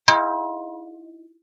Thin bell ding 2
bell chime ding dong short sound effect free sound royalty free Sound Effects